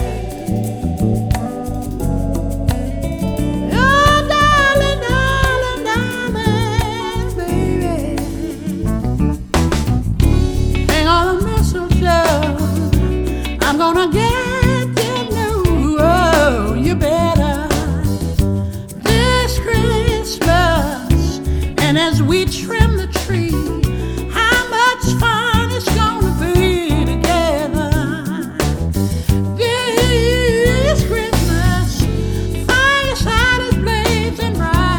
# Holiday